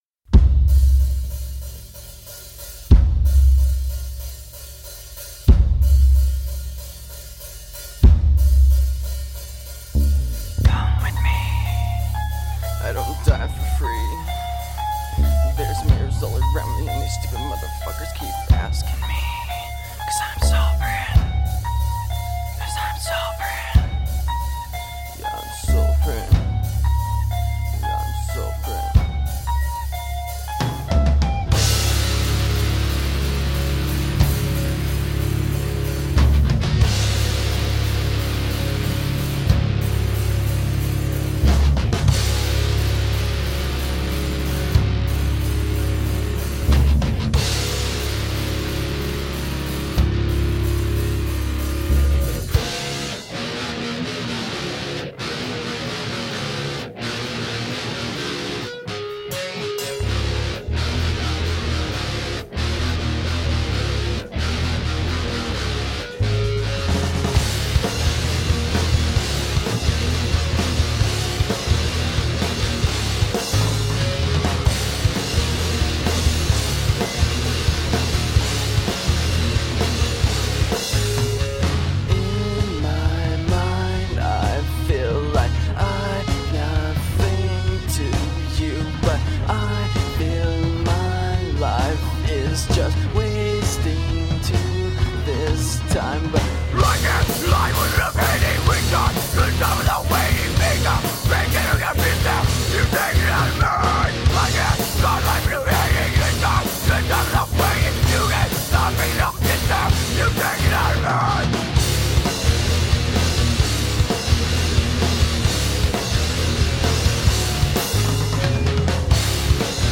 Soulful hard rock.
Tagged as: Hard Rock, Rock, Hard Rock